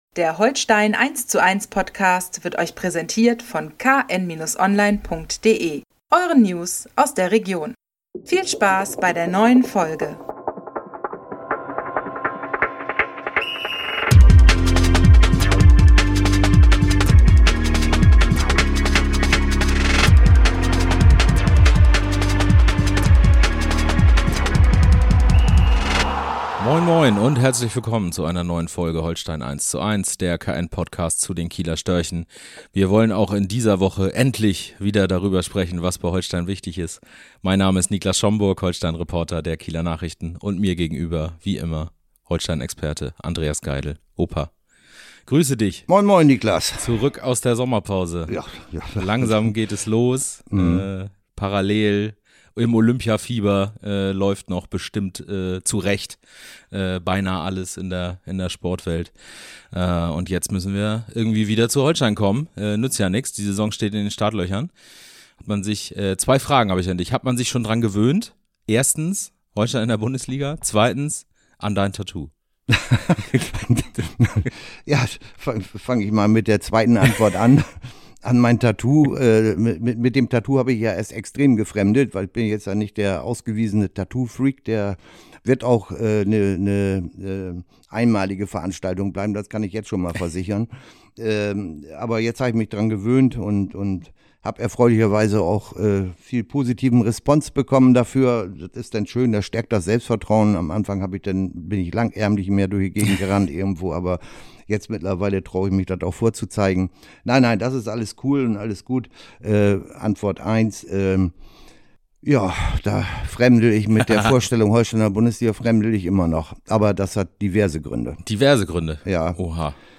Als gallisches Dorf in der Sisyphos-Liga ~ Holstein eins zu eins - Der Fußball Talk Podcast